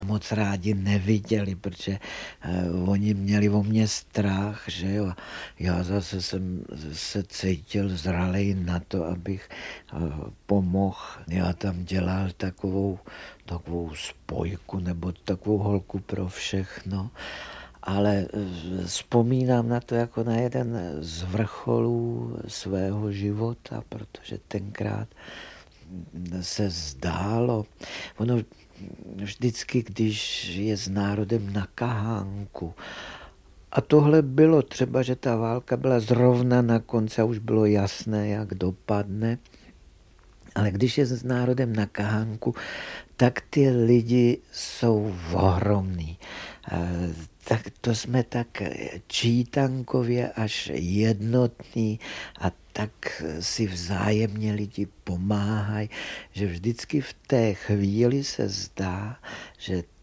Audiobook
Read: František Nepil